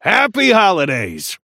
Shopkeeper voice line - Happy holidays!